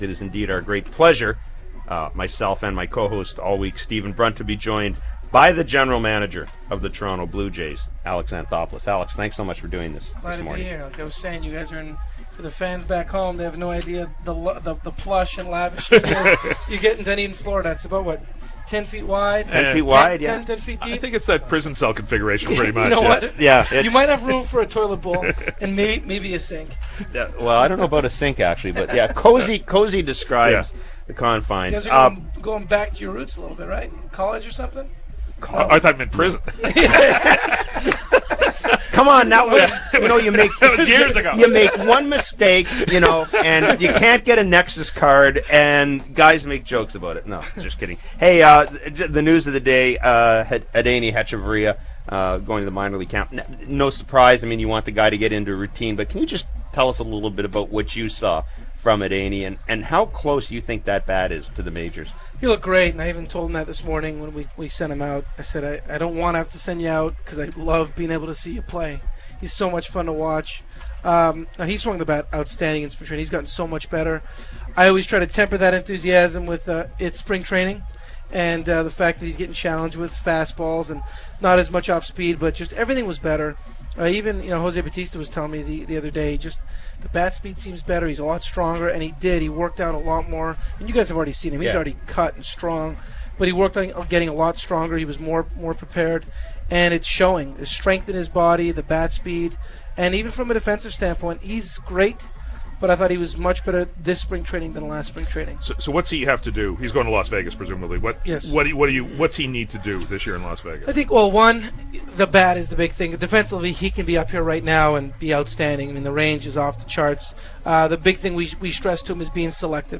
FAN590: Alex Anthopoulos interview on The Jeff Blair Show
Toronto Blue Jays GM Alex Anthopoulos joins Jeff Blair and guest co-host Stephen Brunt in studio in Dunedin, Florida to discuss free agents, Travis Snider in pre season and options for Opening Day.